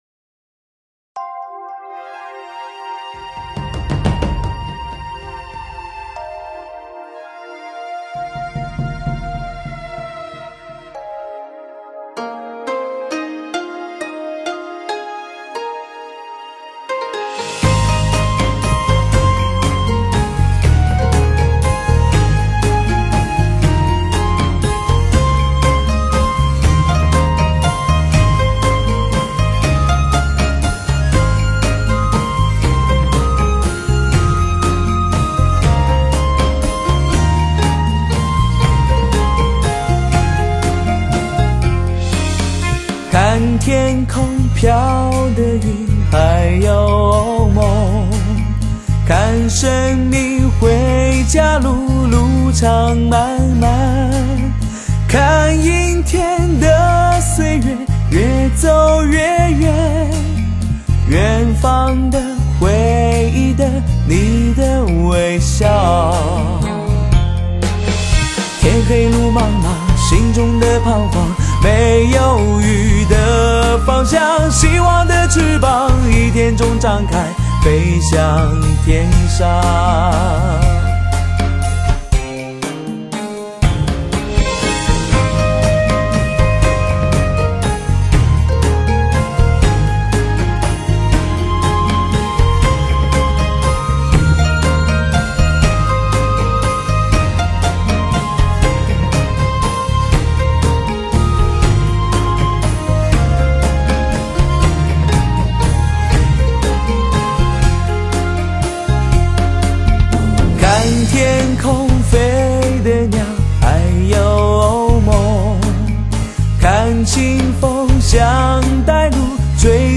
极度Hi-Fi享受 中国首张全真空技术天碟